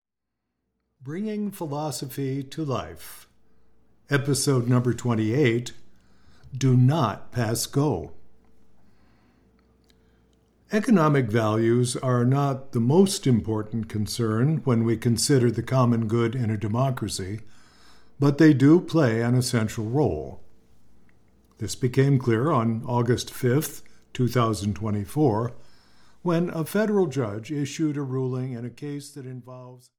Do Not Pass Go! (EN) audiokniha
Ukázka z knihy